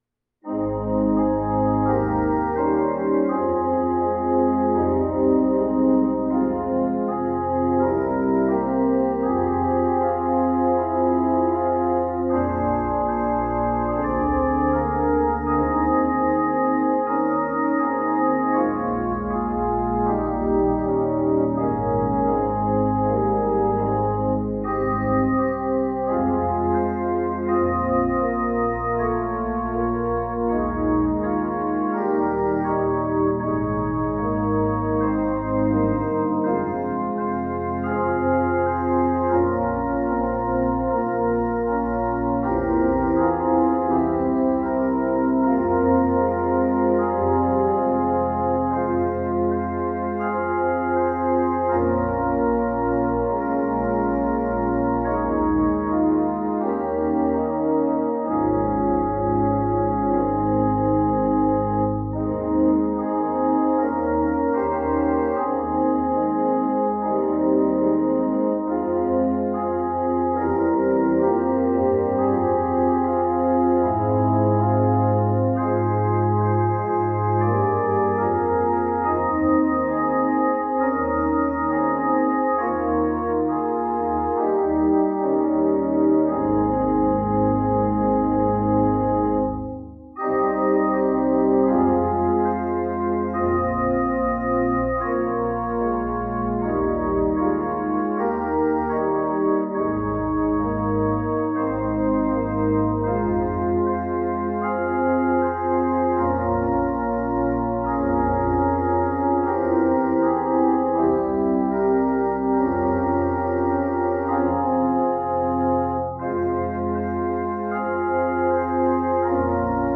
This is a setting of the beautiful hymn “Now the Day Is Over”. Here I’ve set the melody twice: once very softly in the pedal and again more loudly in the manuals.